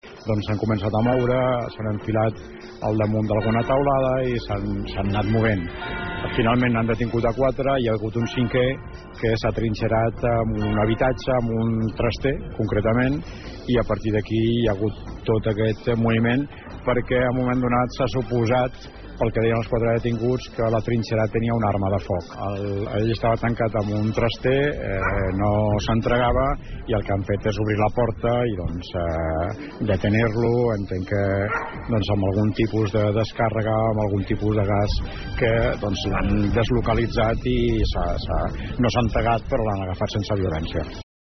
Ho ha explicat l’alcalde de Tordera, Joan Carles Garcia.